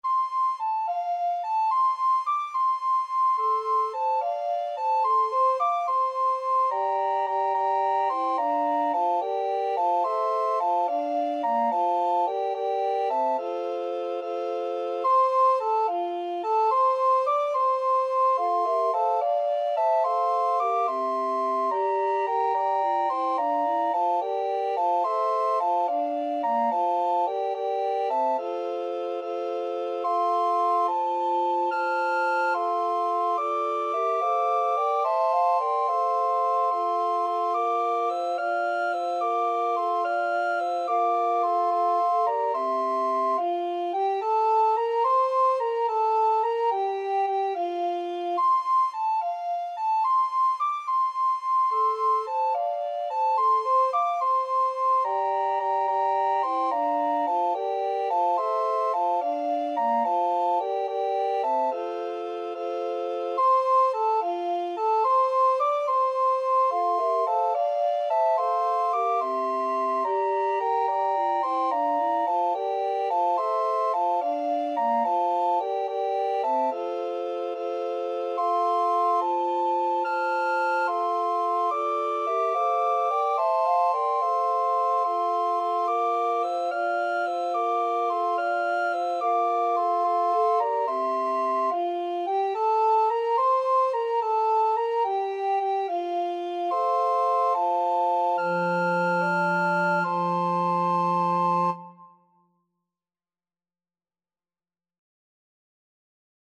Free Sheet music for Recorder Quartet
Bass RecorderSoprano RecorderAlto RecorderTenor Recorder
6/8 (View more 6/8 Music)
F major (Sounding Pitch) (View more F major Music for Recorder Quartet )
Maestoso . = c. 72
Recorder Quartet  (View more Intermediate Recorder Quartet Music)
Traditional (View more Traditional Recorder Quartet Music)